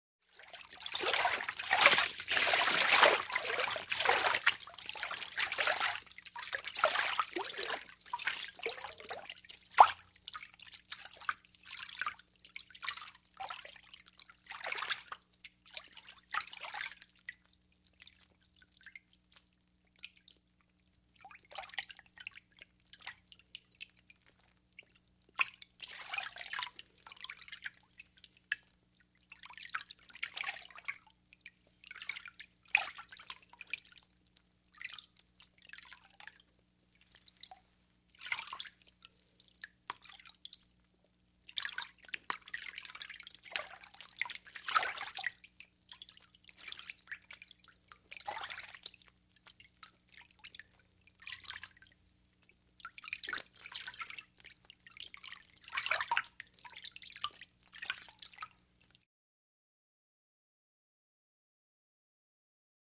Water Trickle 2; Light Water Splash And Movement.